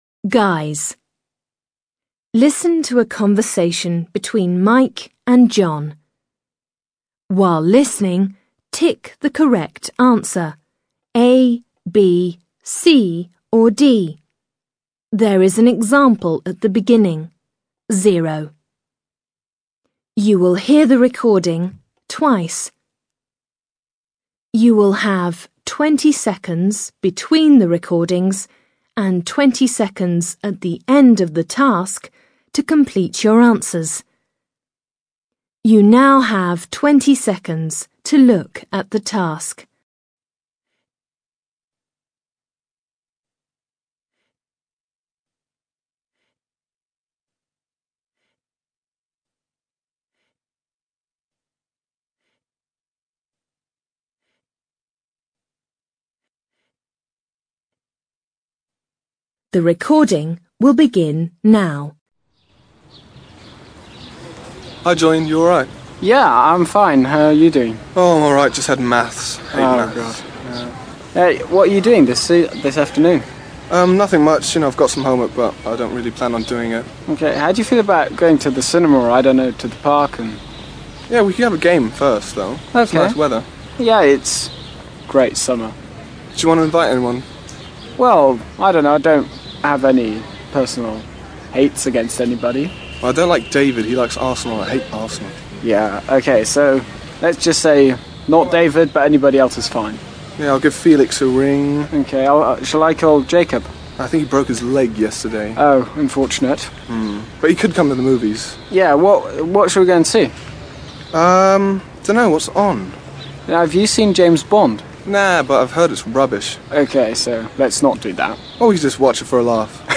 Alltagsgespräch